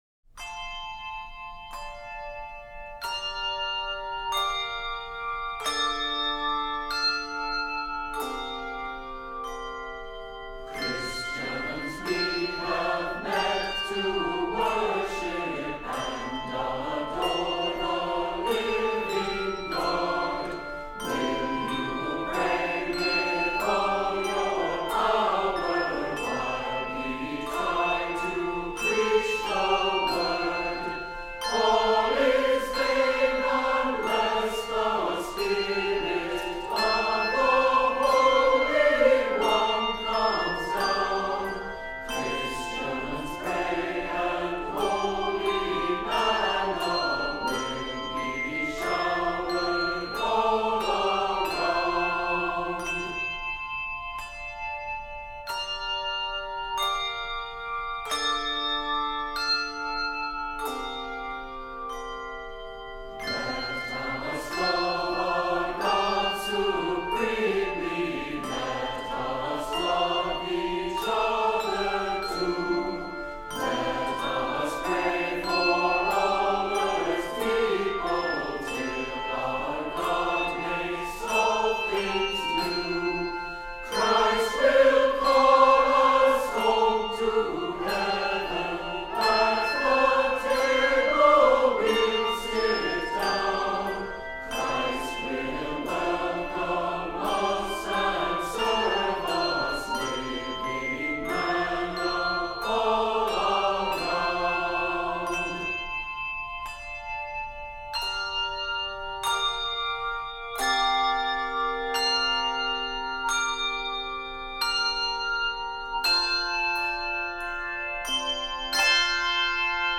Voicing: Handbells 3 Octave and Voice